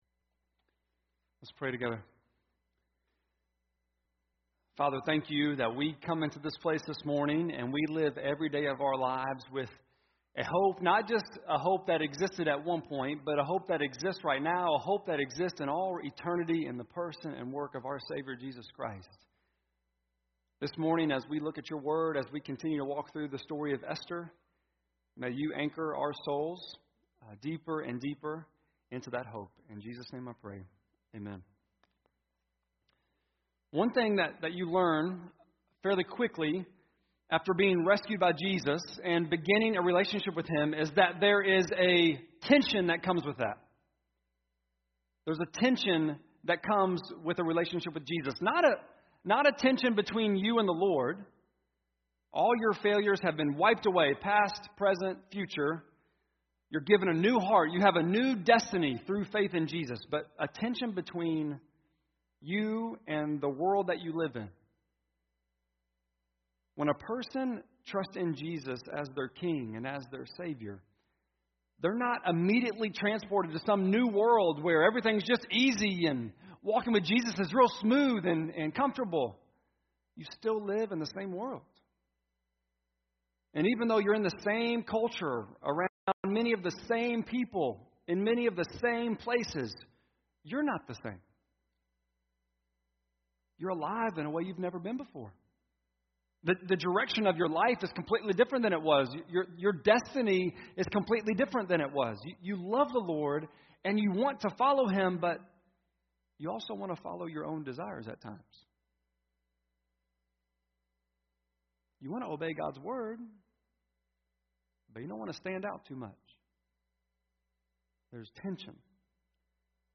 Esther-2.1-8-sermon.mp3